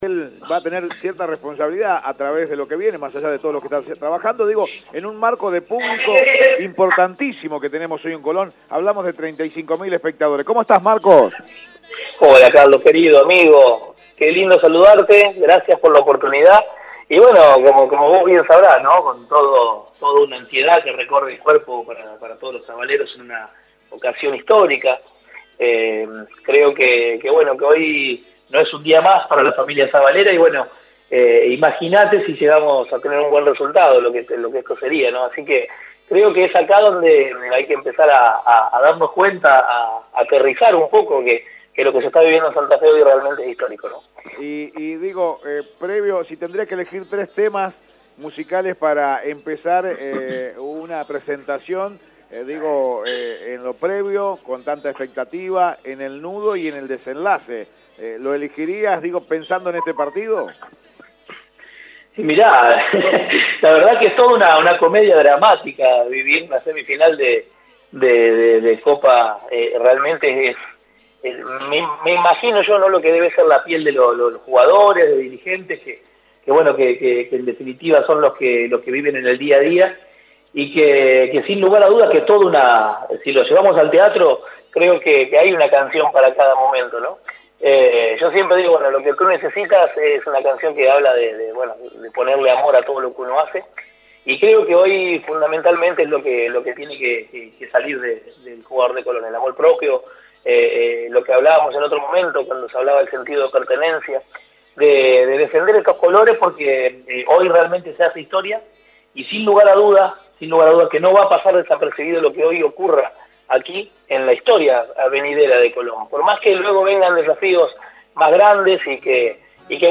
Sabaleros de la cumbia santafesina, dialogaron con Radio Eme en la previa del partido